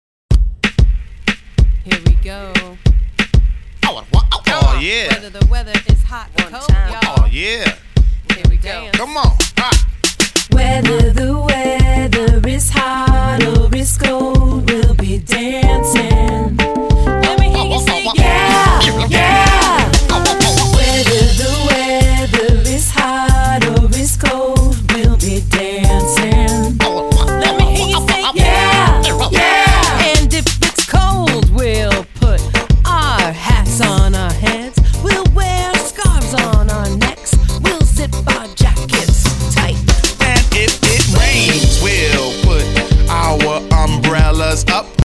Rap and sing about counting, friendship, shapes and coins